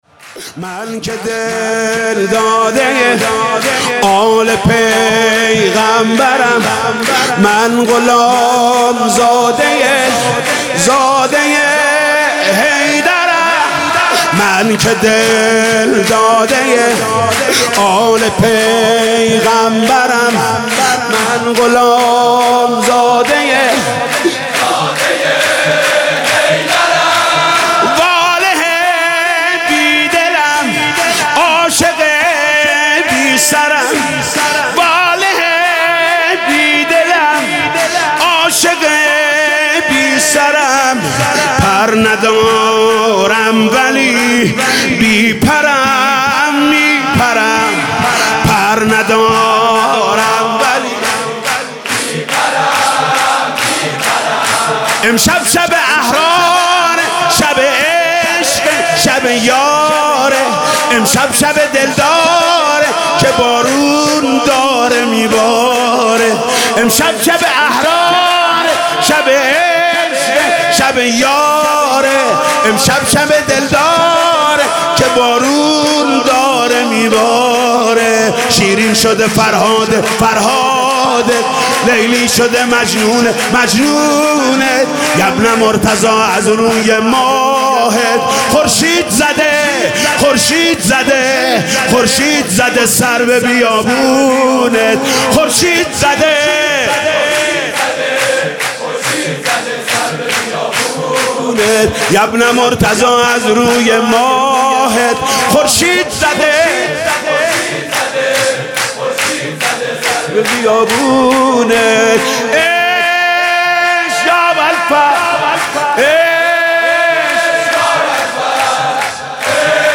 سرود: من که دلداده ی آل پیغمبرم